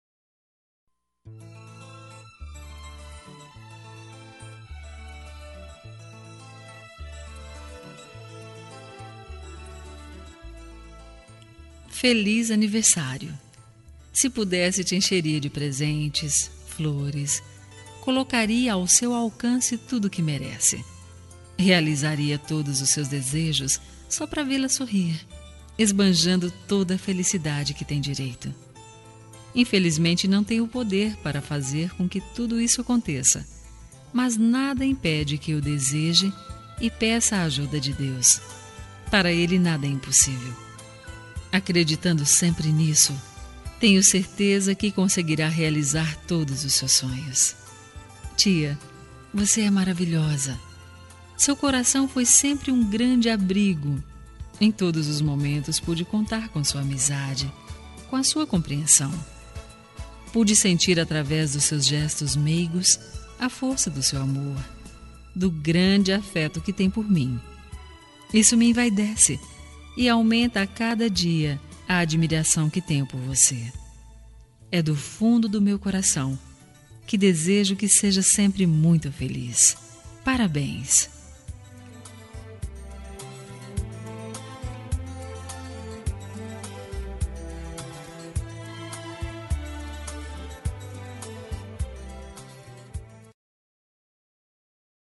Aniversário de Tia – Voz Feminina – Cód: 9610